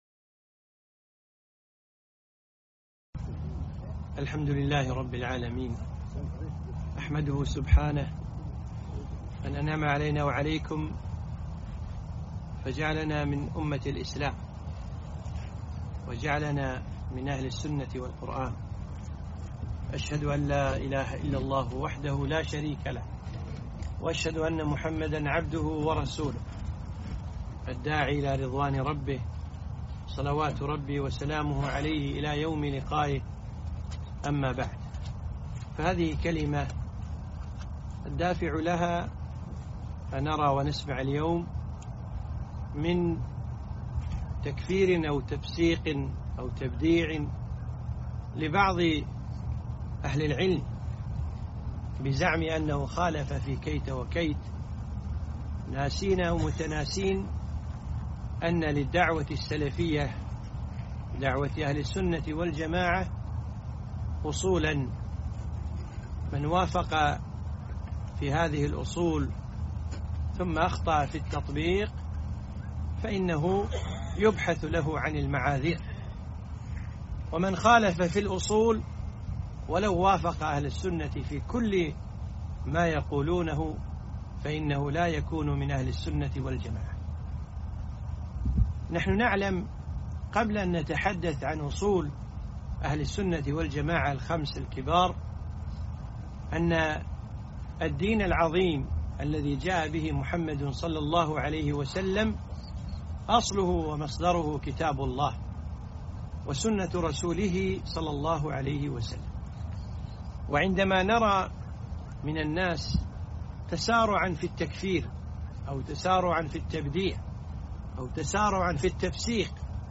محاضرة - أصول أهل السنة